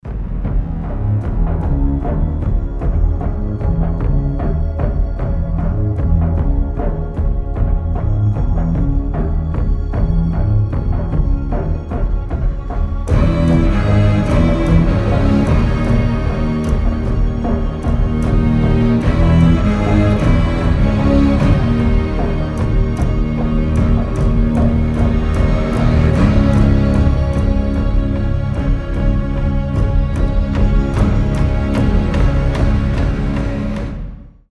• Качество: 320, Stereo
без слов
пугающие
из фильмов
оркестр
эпичные
тревога